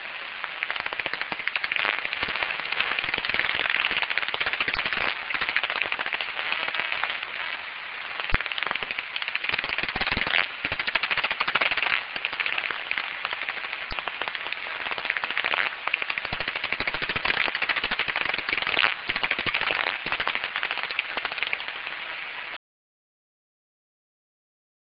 enregistrement des cris d'écholocation d'un vespertilion de Daubenton (Pettersson D200 réglé sur 49 kHz - 08/09/2008 - Hamois, Belgique)